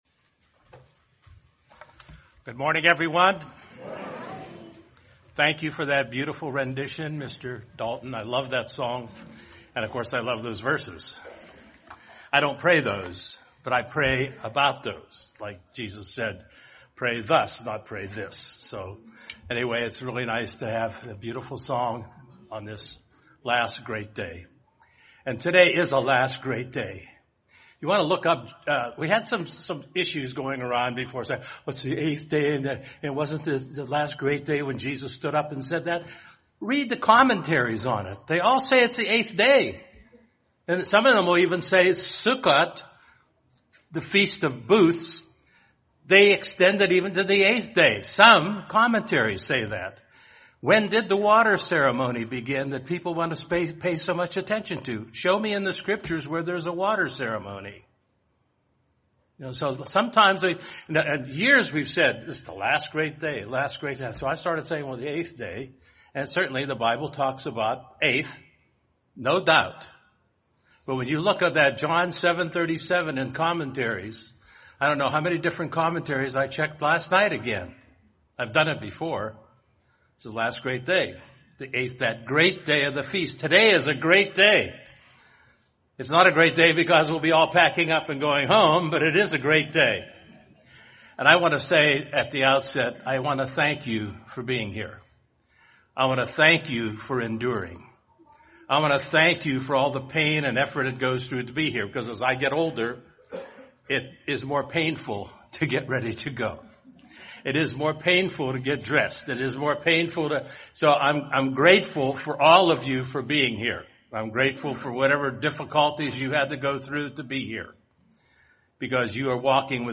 This sermon was given at the Cincinnati, Ohio 2019 Feast site.